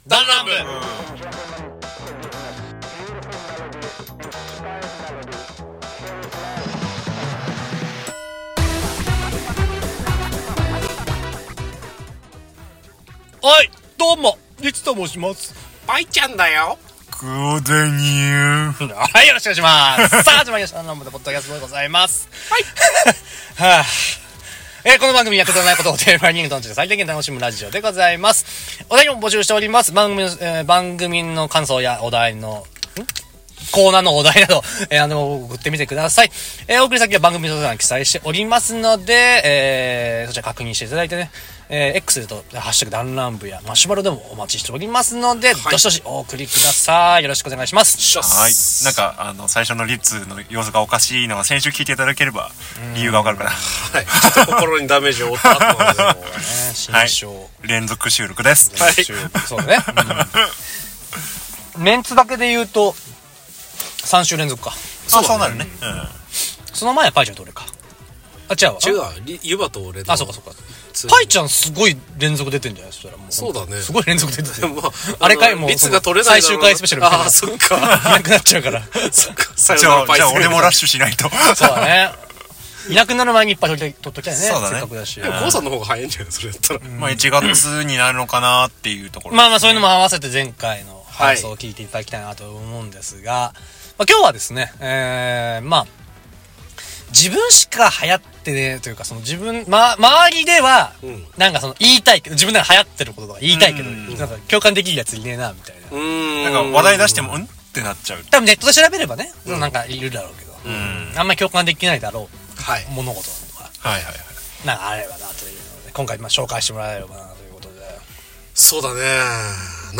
だんらん部 -アラサー男達の勝手なる雑談会-
くだらない ことを 最大限 に楽しむラジオ番組 何人で、何の話をするかはいつもバラバラ。 楽しむことを妥協しない社会人 7人組 のお話。